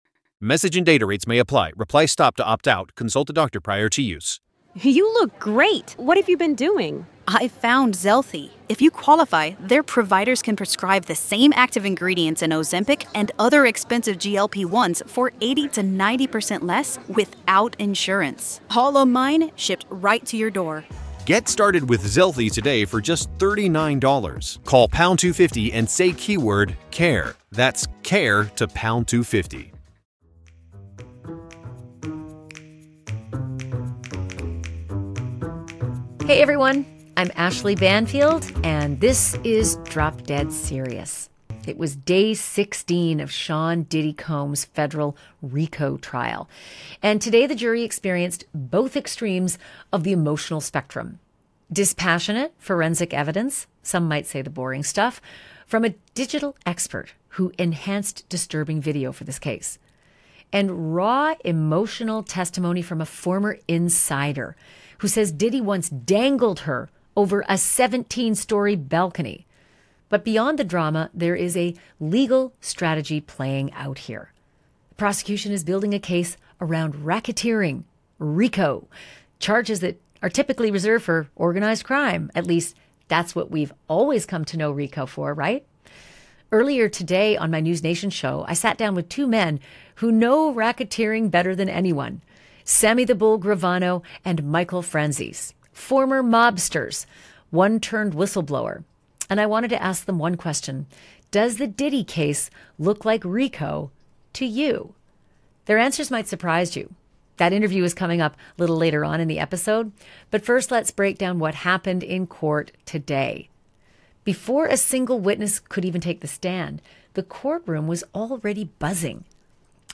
In this episode of Drop Dead Serious, Ashleigh Banfield breaks it all down, including a flurry of “I don’t remembers” during cross-examination, drug admissions, and emotional cracks under pressure. PLUS: a special clip from Ashleigh’s NewsNation sit-down with former mobsters Sammy “The Bull” Gravano and Michael Franzese, who reveal whether they think the government has a real RICO case.